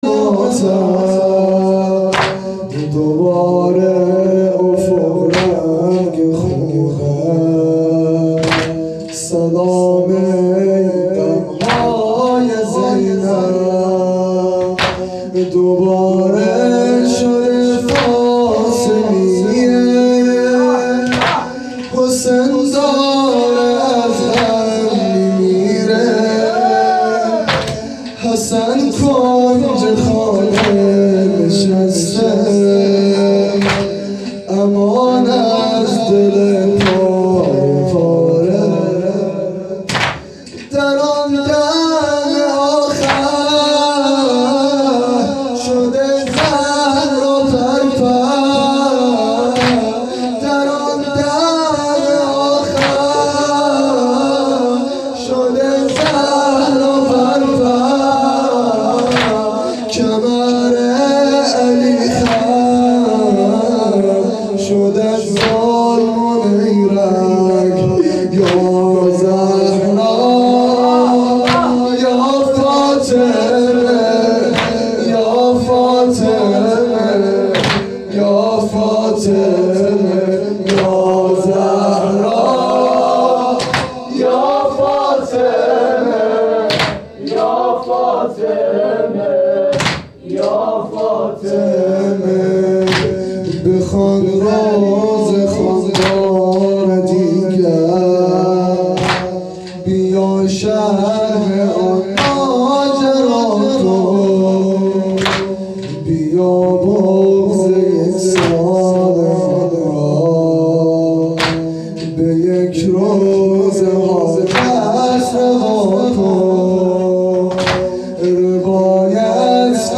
مراسم هفتگی۹۳/۱۱/۱۵
شور